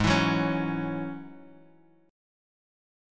Ab7sus4#5 chord